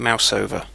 Ääntäminen
Vaihtoehtoiset kirjoitusmuodot mouse-over Ääntäminen UK Haettu sana löytyi näillä lähdekielillä: englanti Käännöksiä ei löytynyt valitulle kohdekielelle.